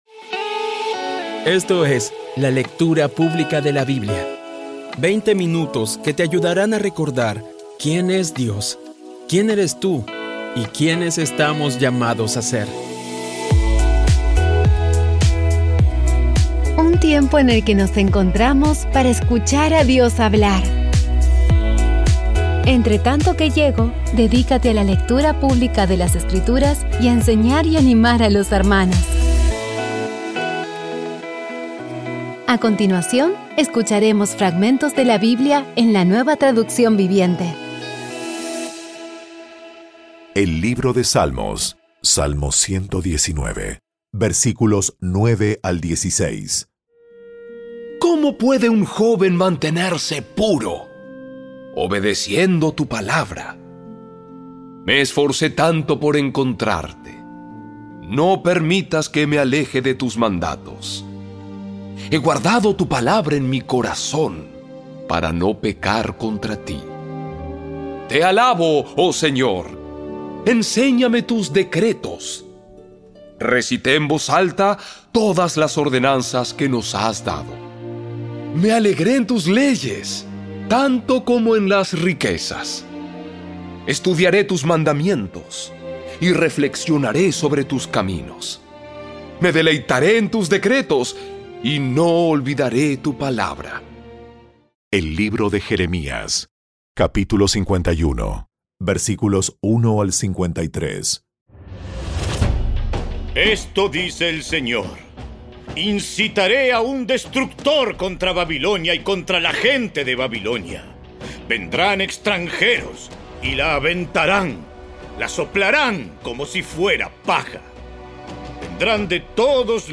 Audio Biblia Dramatizada Episodio 297
Poco a poco y con las maravillosas voces actuadas de los protagonistas vas degustando las palabras de esa guía que Dios nos dio.